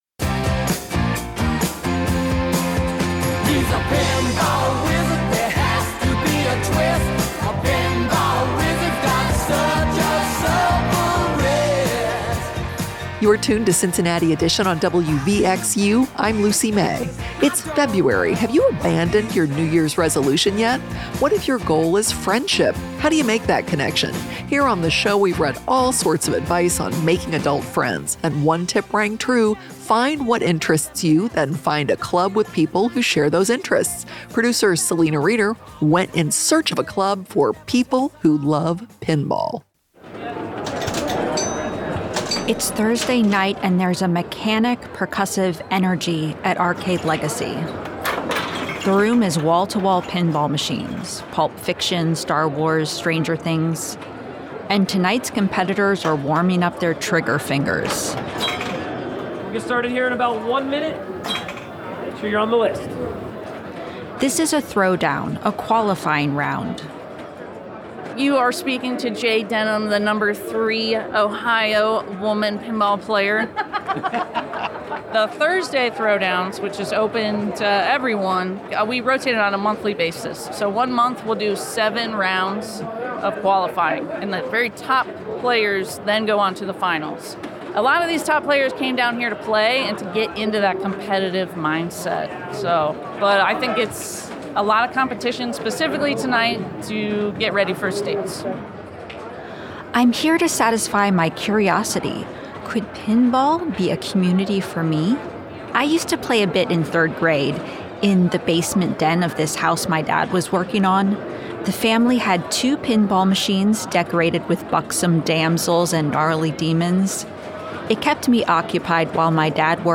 We ask top players Yes, they do use “THE song” at the beginning of this feature.
It’s still the most recognizable pinball song, and it’s great as a music bed for an intro about pinball.